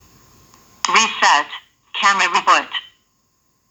Прикрепил то, что говорит камера